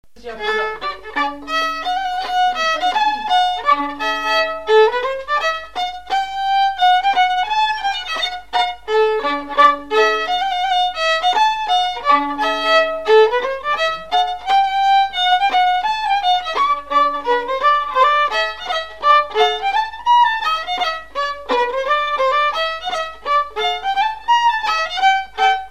Mazurka
danse : mazurka
Enquête Arexcpo en Vendée
instrumentaux au violon
Pièce musicale inédite